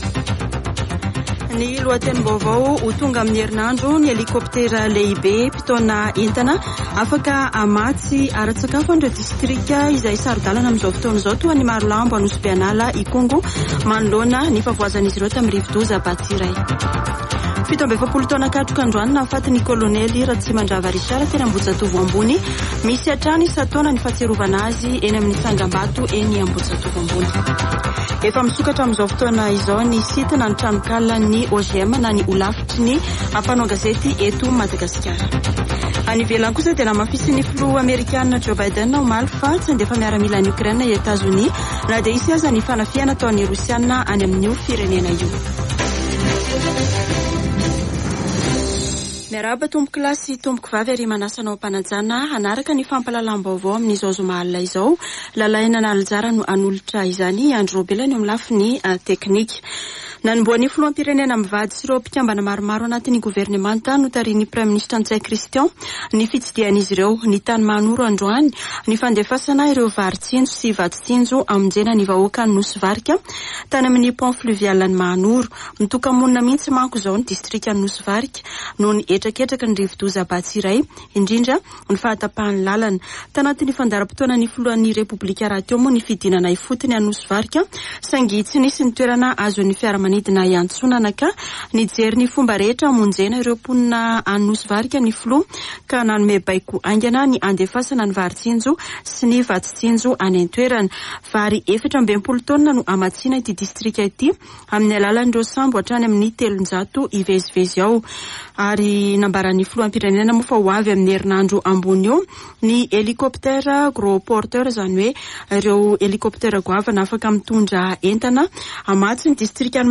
[Vaovao hariva] Zoma 11 febroary 2022